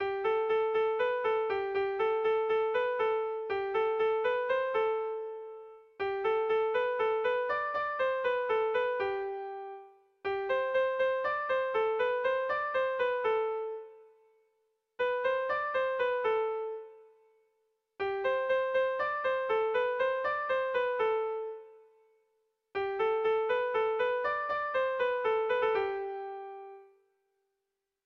Irrizkoa
Hamarreko txikia (hg) / Bost puntuko txikia (ip)
ABDDB